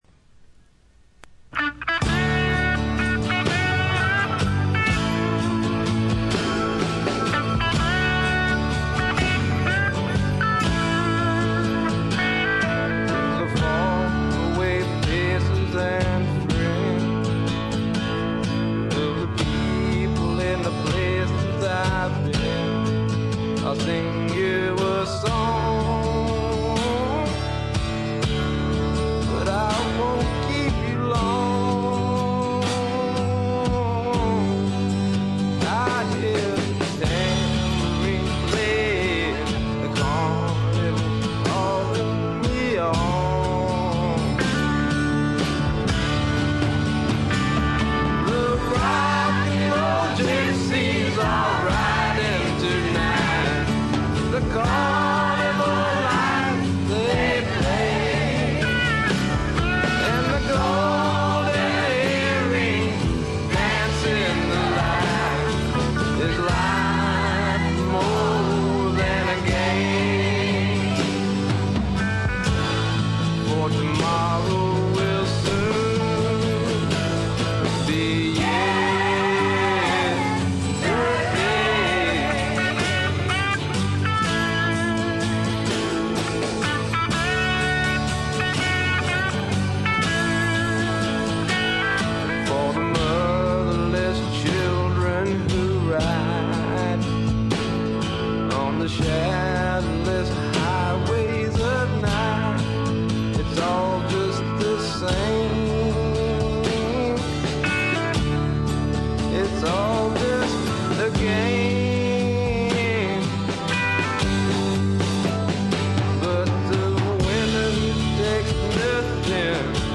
軽微なチリプチ少し。
まさしくスワンプロックの真骨頂。
試聴曲は現品からの取り込み音源です。